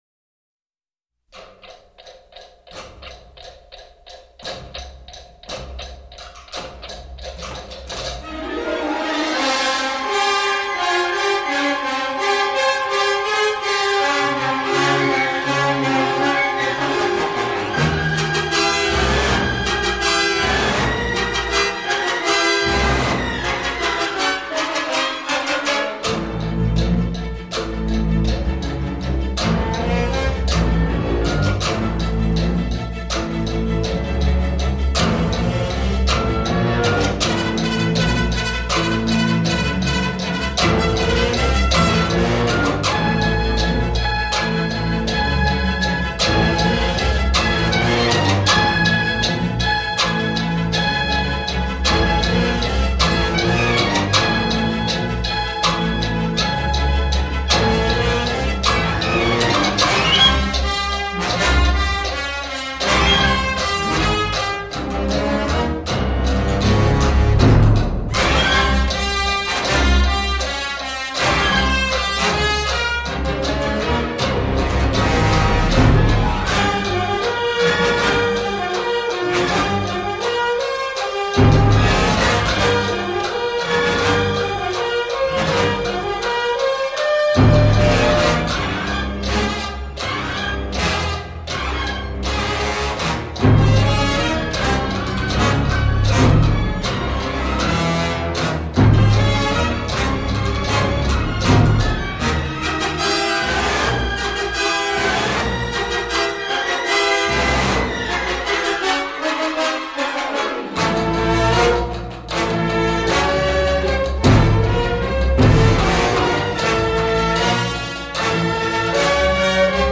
sublime score martial, romantique et exotique
percussions exotiques
métriques sophistiquées